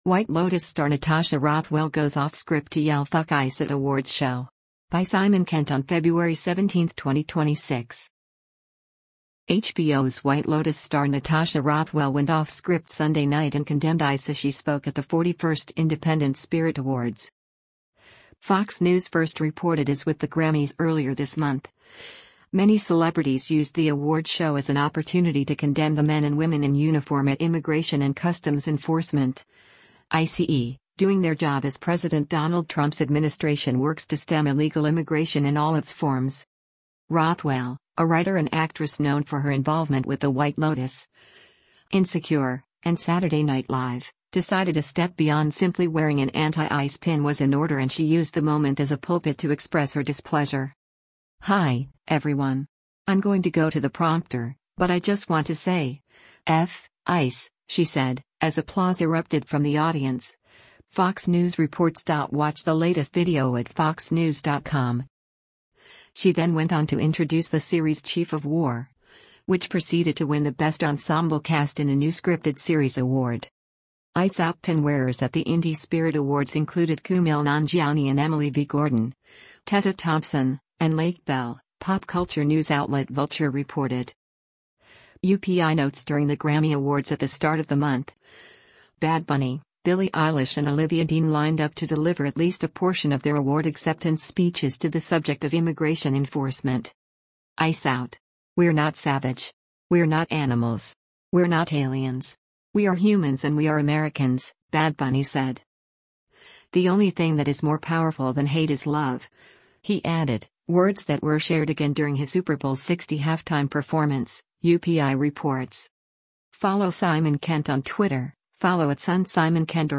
LOS ANGELES, CALIFORNIA - FEBRUARY 15: Natasha Rothwell speaks onstage during the 2026 Fil
HBO’s White Lotus star Natasha Rothwell went off-script Sunday night and condemned ICE as she spoke at the 41st Independent Spirit Awards.
“Hi, everyone! I’m going to go to the prompter, but I just want to say, ‘f— ICE,’” she said, as applause erupted from the audience, FOX News reports.